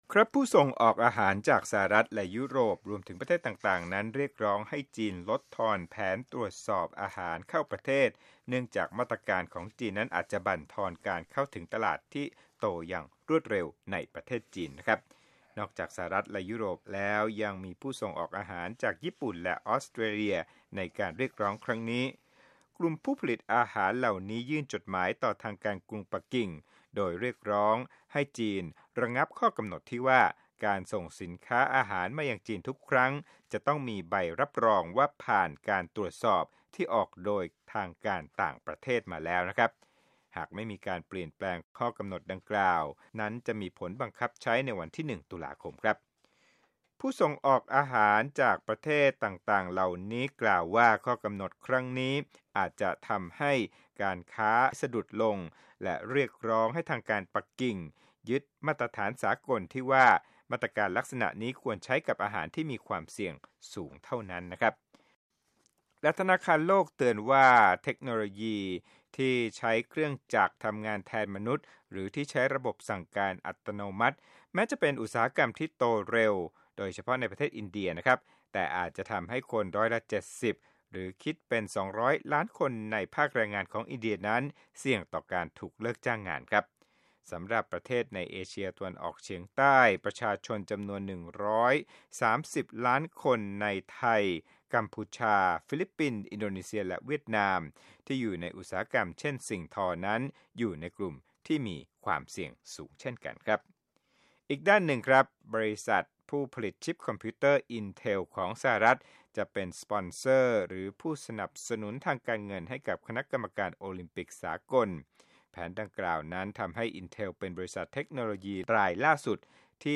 ข่าวธุรกิจ 6/22/2017